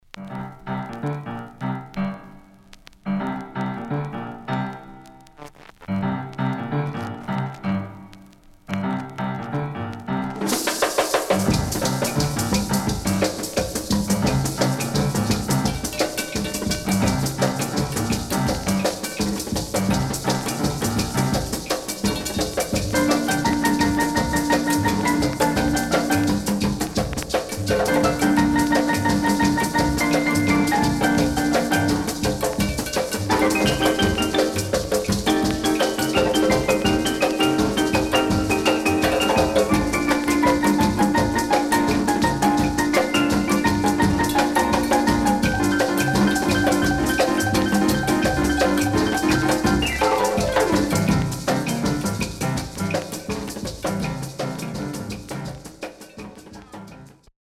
Rare.Jazzyかつ陽気なCarib Musicが堪能できる素晴らしいAlbum
SIDE A:全体的にノイズ入ります。